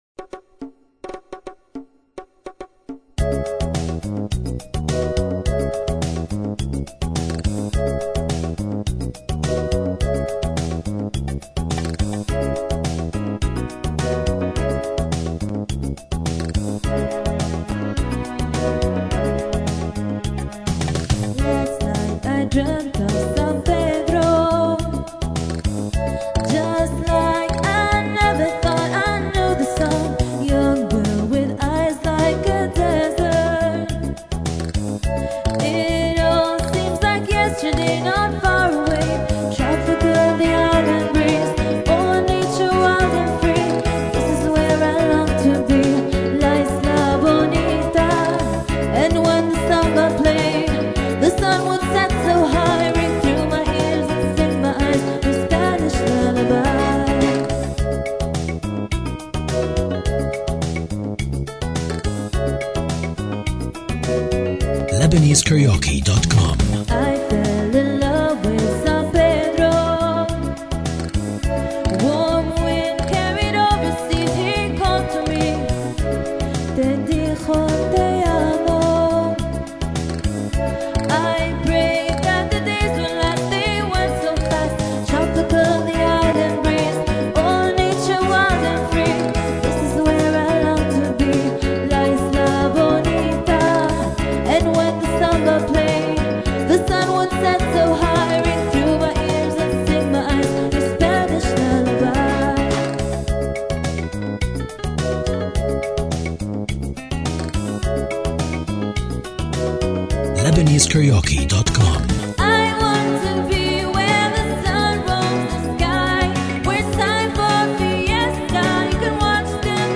English Karaoke Talented Singers